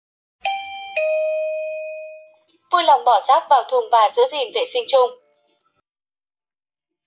Tải âm thanh thông báo Vui lòng Bỏ rác vào Thùng và Giữ gìn vệ sinh chung Mp3
Thể loại: Tiếng chuông, còi
am-thanh-thong-bao-vui-long-bo-rac-vao-thung-va-giu-gin-ve-sinh-chung-www_tiengdong_com.mp3